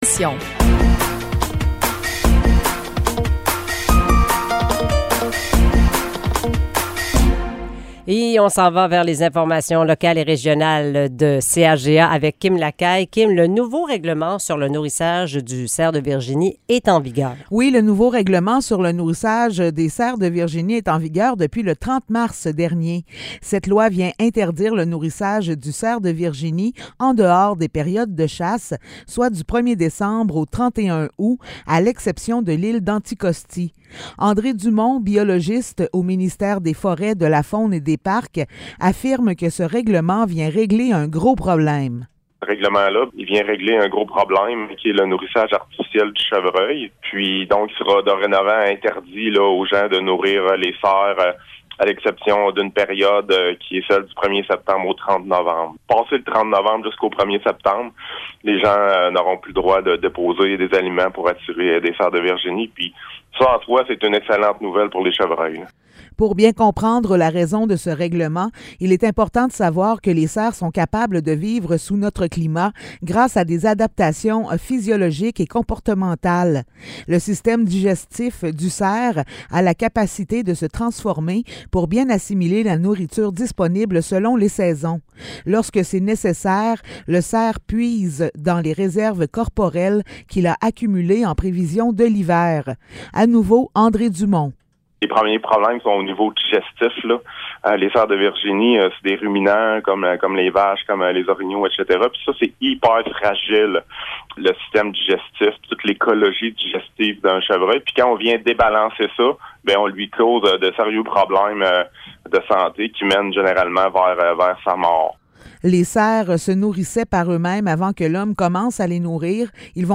Nouvelles locales - 7 avril 2023 - 7 h